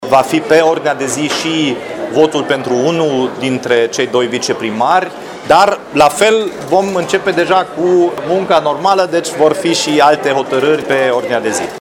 Primarul Timișoarei, Dominic Fritz, spune că va fi supusă la vot și alegerea unuia dintre viceprimari.